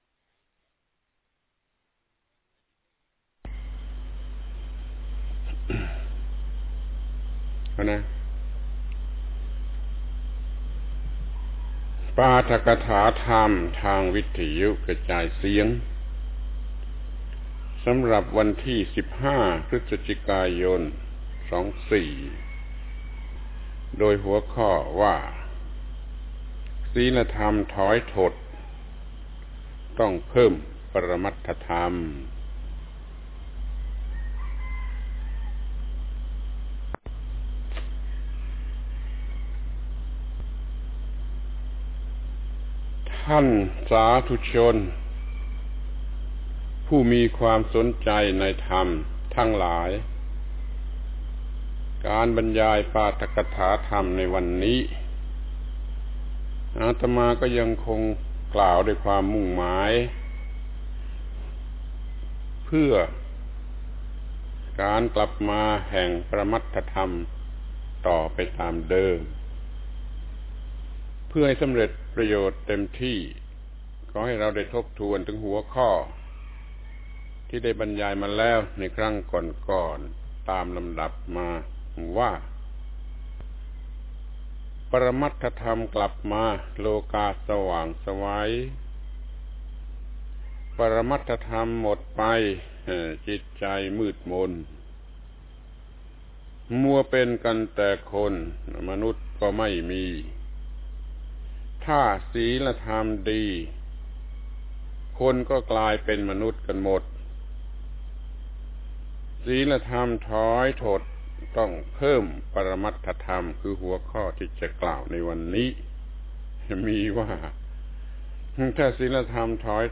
ปาฐกถาธรรมทางวิทยุฯ ปรมัตถธรรมกลับมา ครั้งที่ 5 ศีลธรรมถอยถดต้องเพิ่มปรมัตถธรรม